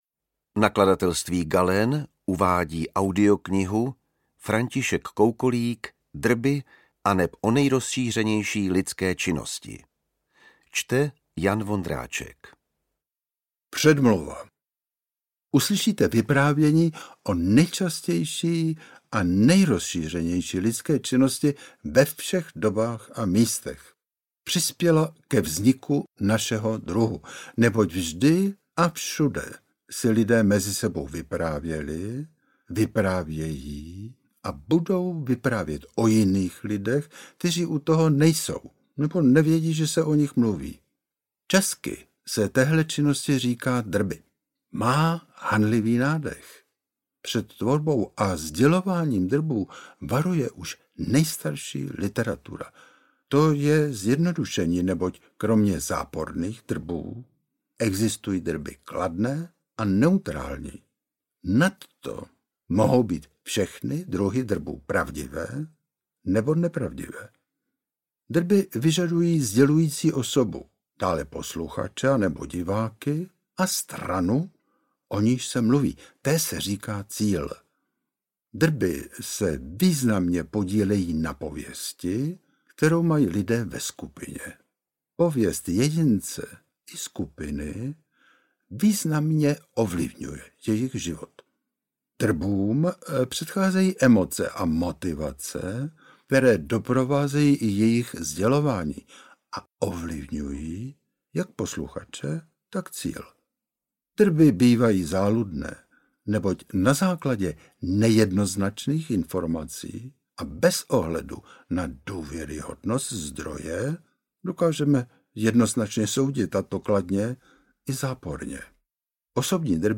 Drby. O nejrozšířenější lidské činnosti audiokniha
Ukázka z knihy
• InterpretJan Vondráček, František Koukolík